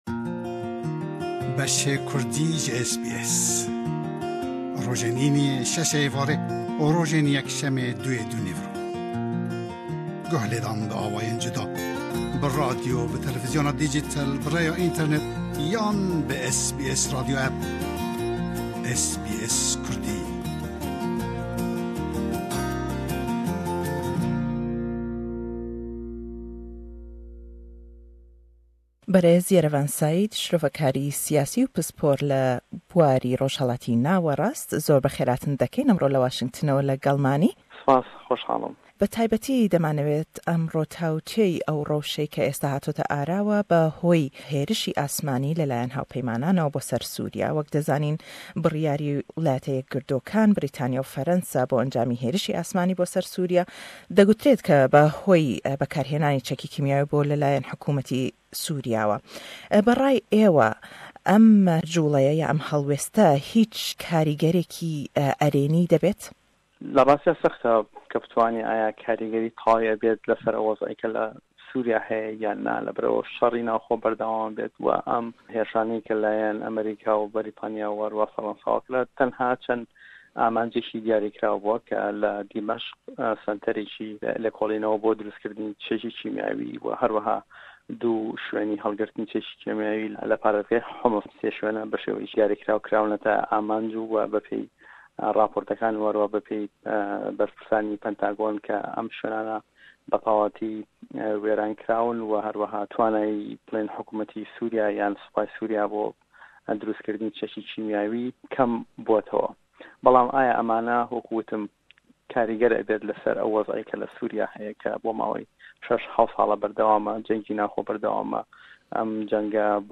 Le em lêdwane da